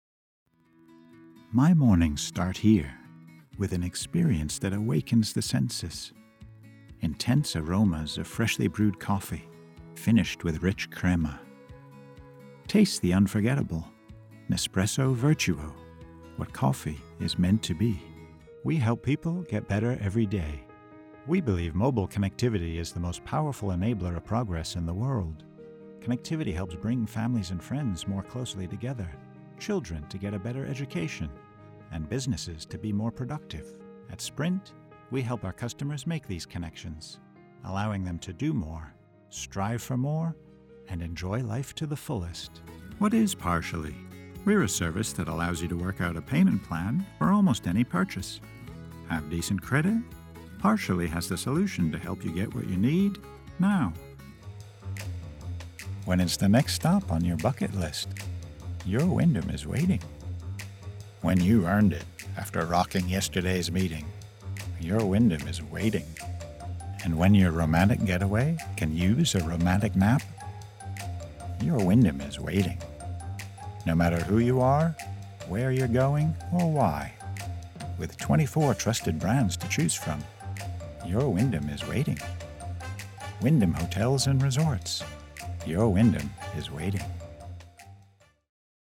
Commercial reel 2
US-New England, US-Southern, US-California, US-General
Middle Aged